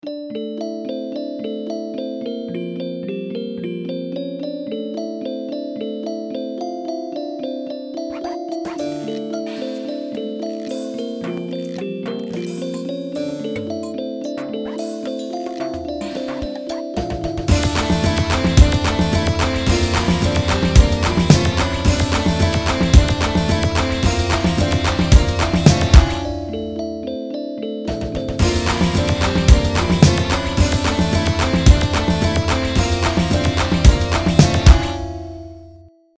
track 1 - Electronic - Young Composers Music Forum
not done yet, I was making a song with a LOT of drums when I realized the chorus looks like decorated congas...